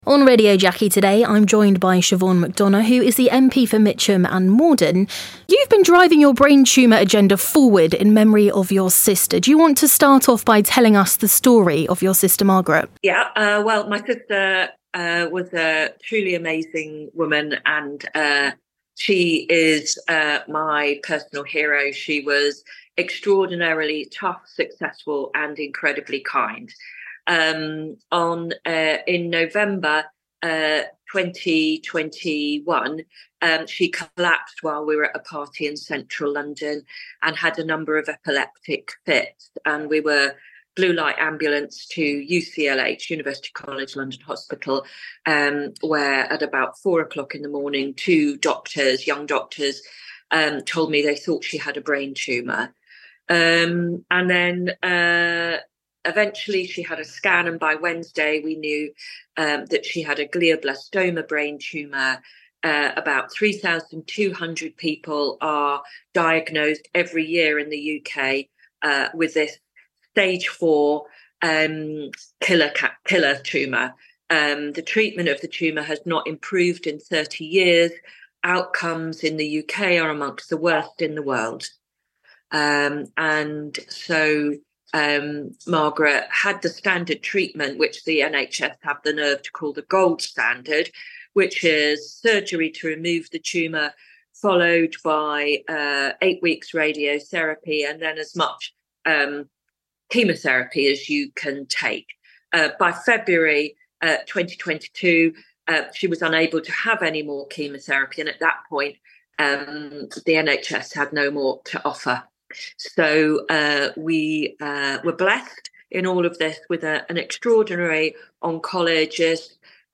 Radio Jackie Interview with Siobhain McDonagh, MP for Mitcham and Morden.